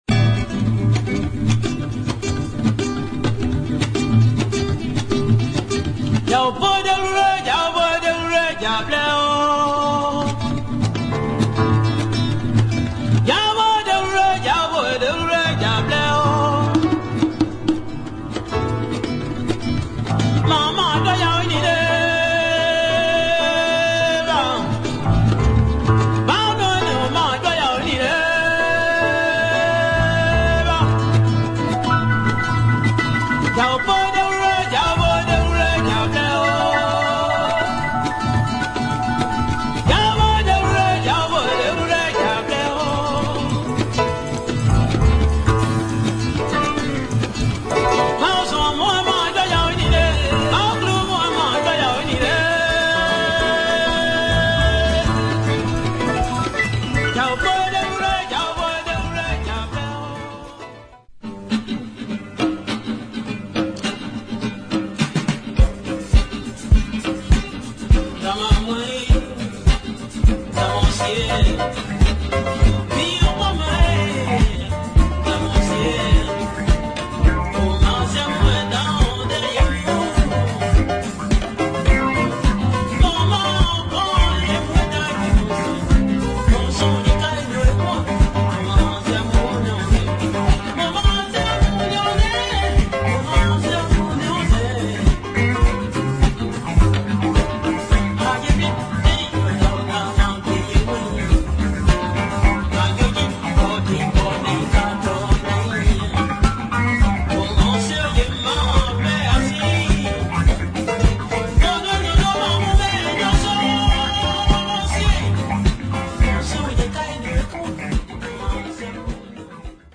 African soulful folk, jazz and afro disco pop